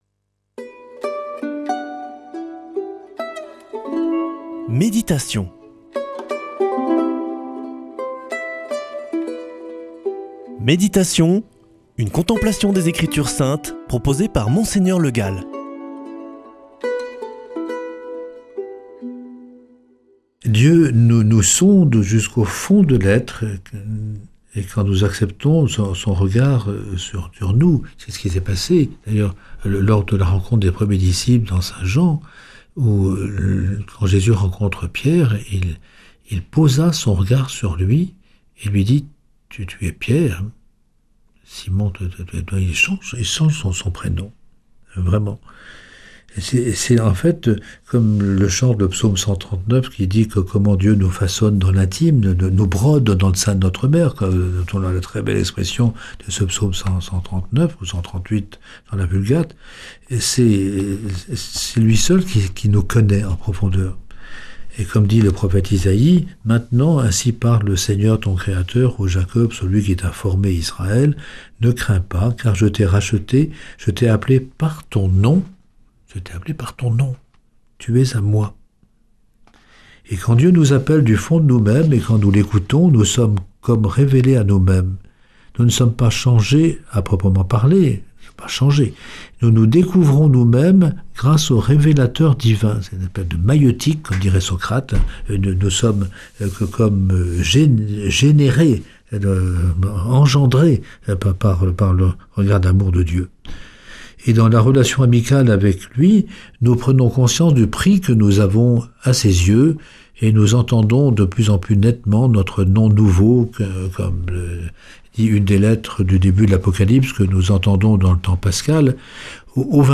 Présentateur